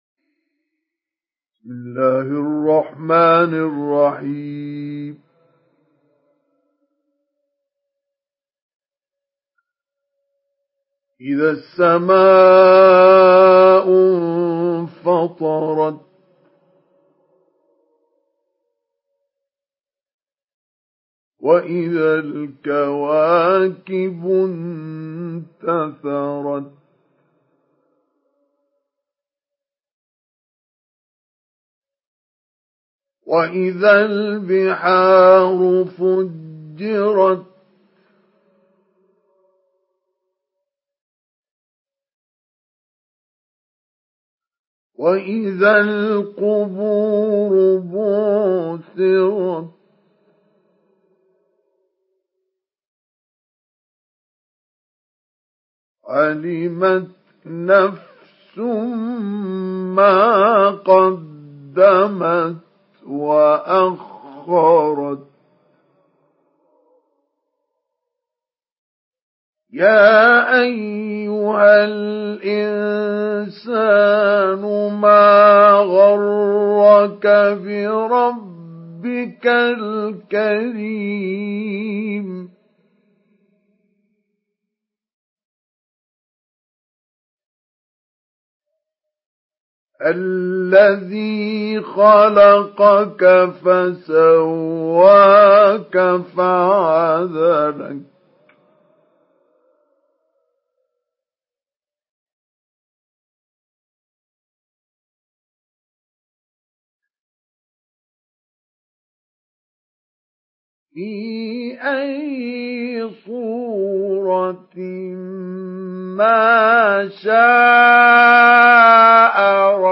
Surah Al-Infitar MP3 in the Voice of Mustafa Ismail Mujawwad in Hafs Narration
Mujawwad Hafs An Asim